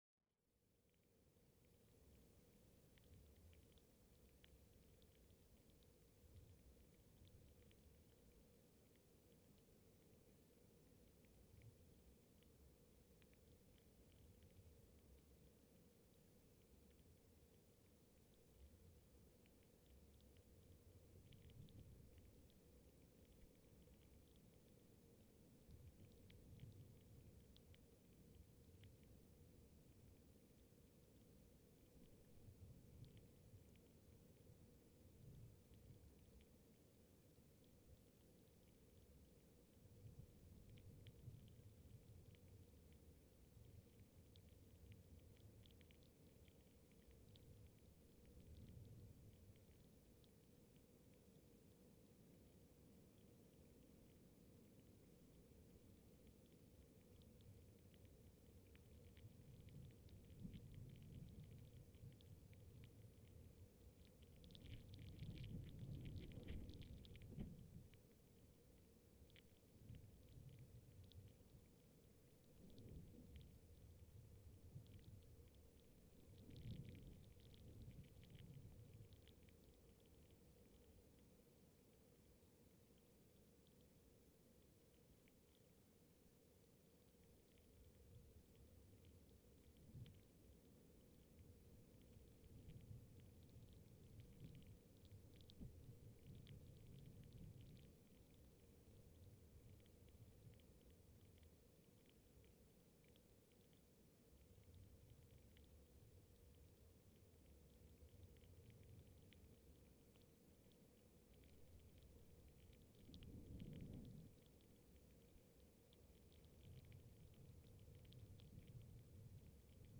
PFR10380, 1-10, 141224, wind, seaweed, Gollwitz, Poel Island, Germany